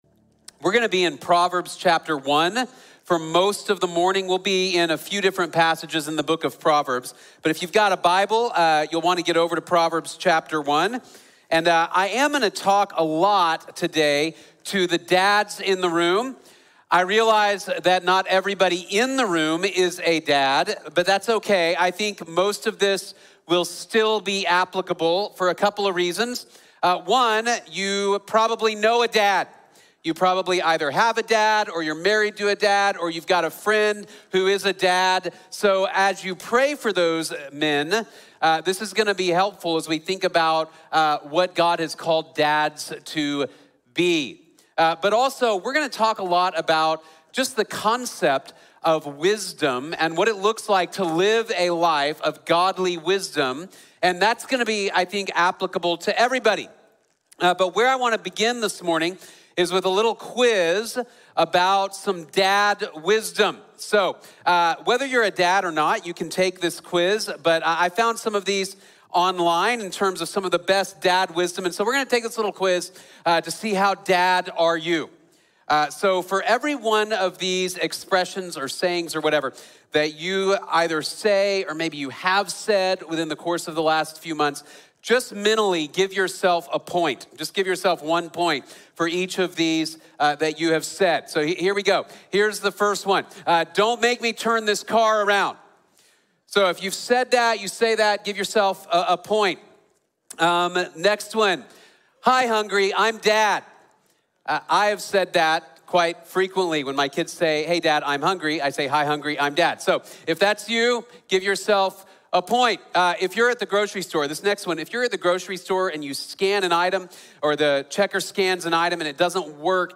The Proverbs Father | Sermon | Grace Bible Church